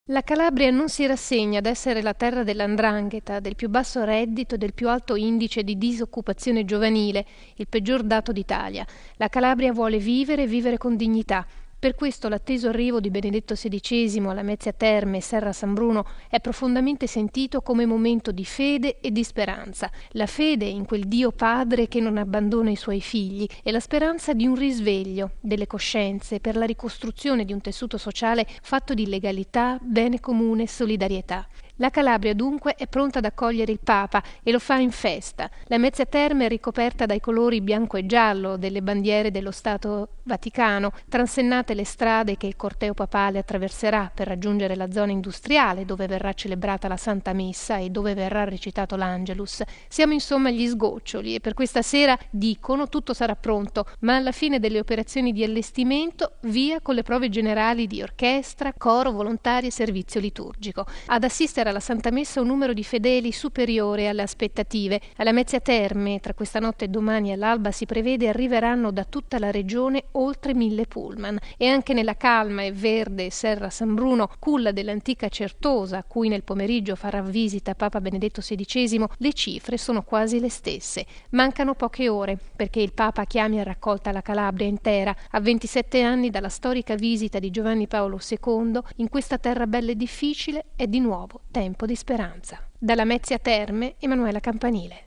Dalla nostra inviata